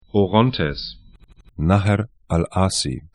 Pronunciation
Orontes o'rɔntɛs Nahr al ‘Āsī 'nahɛr al 'a:si ar Fluss / stream 36°00'N, 36°23'E